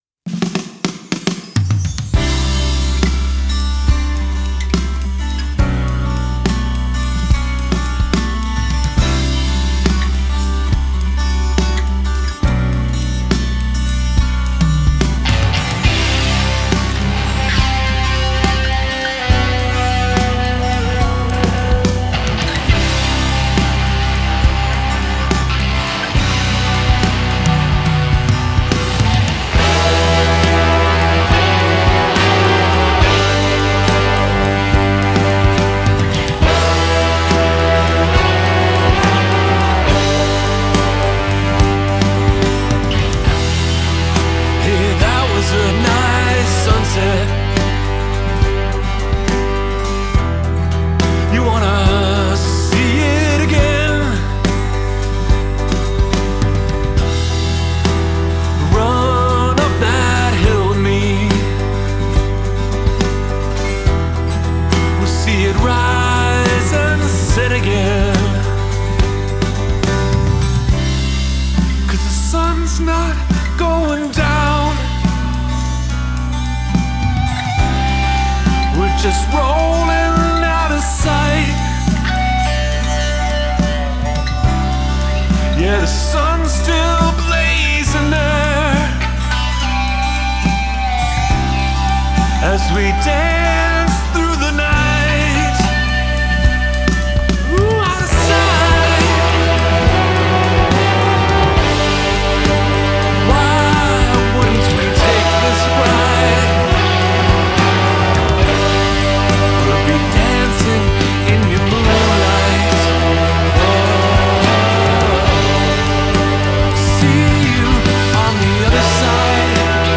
drums, percussion
trumpet
trombone
tenor saxophone
baritone saxophone
backing vocals, wooden flute